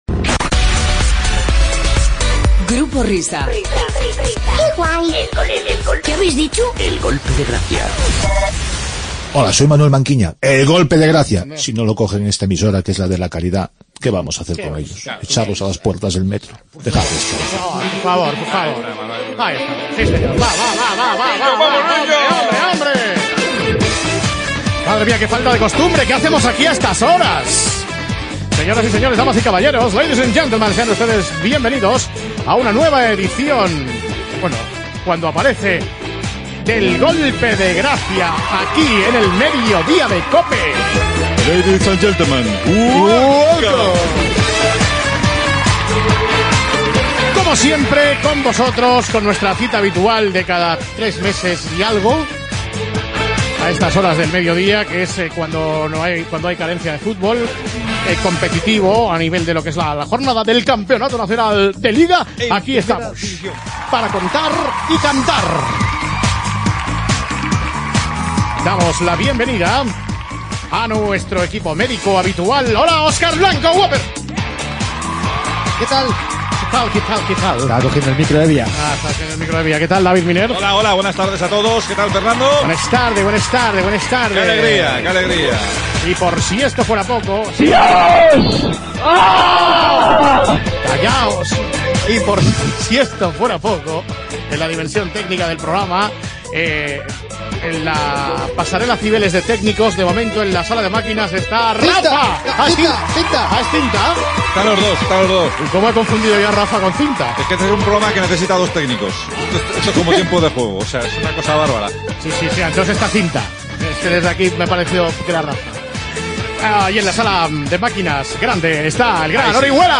Imitació de Carlos Herrera, espai "El espejo" d'Herra en COPE dedicat a un anunci de Securitas Direct, temes musicals, imitació del cantant Julio Iglesias
Entreteniment